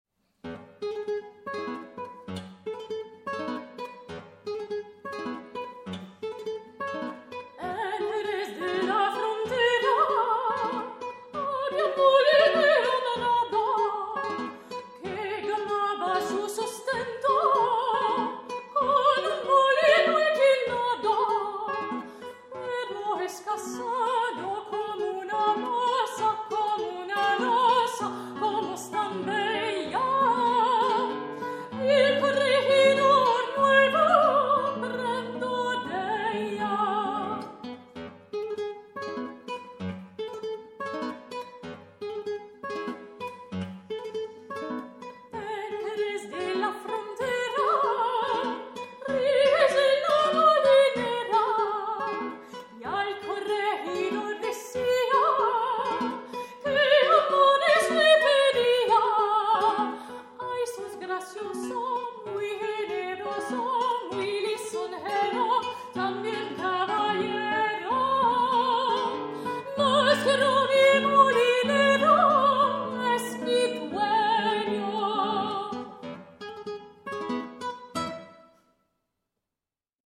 guitariste
soprano
Ils se consacrent à l’interprétations du répertoire classique et contemporain, ainsi qu’à la commande de nouvelles œuvres auprès de compositeurs et compositrices actuels, mettant en avant la complémentarité de la guitare et de la voix.